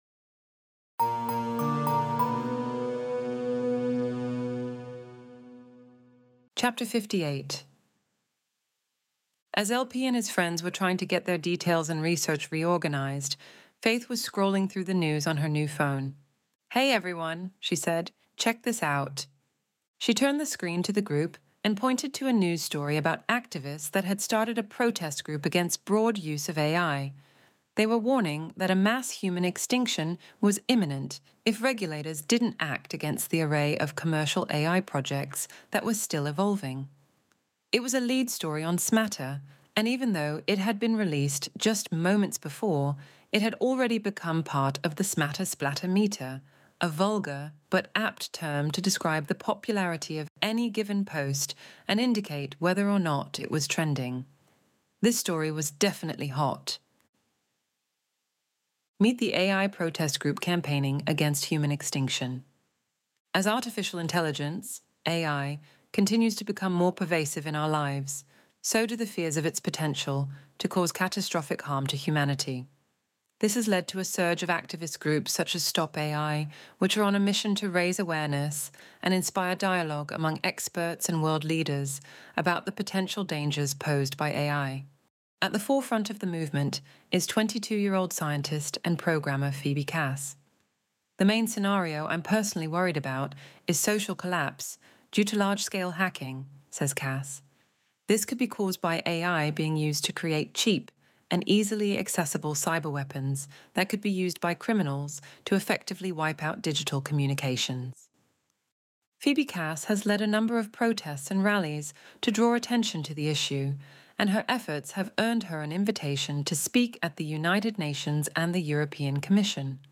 Extinction Event Audiobook Chapter 58